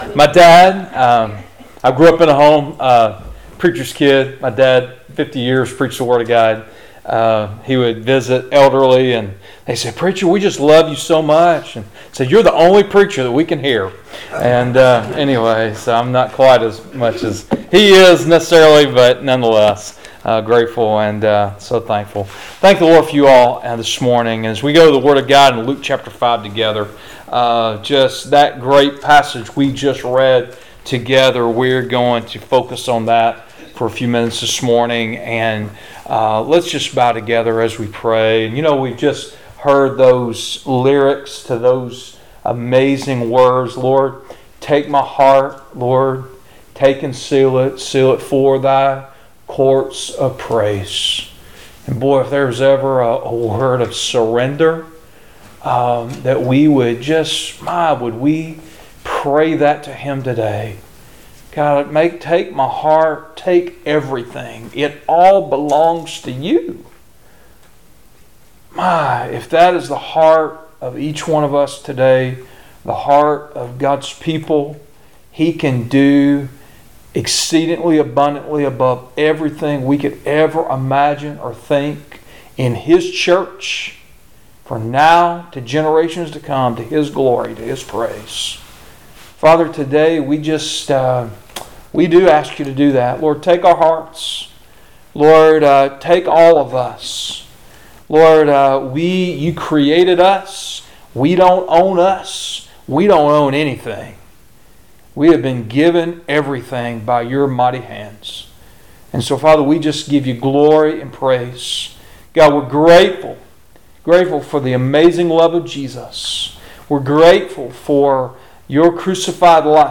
All Sermons Working together for the Gospel Luke 5:7-16 30 January 2022 Topic